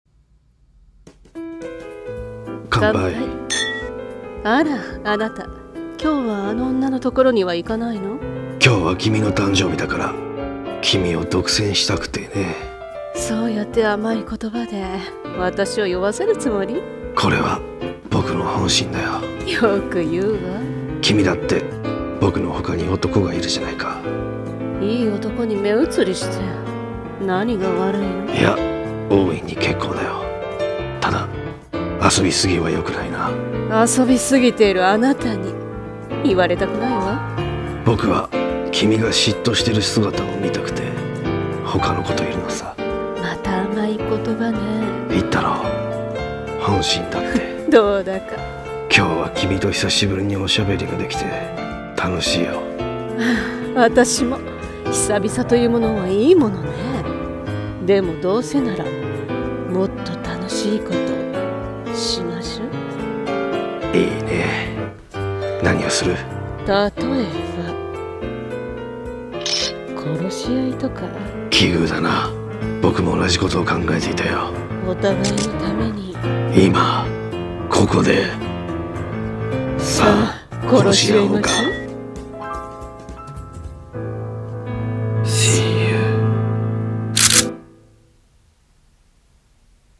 【声劇】ー密談ー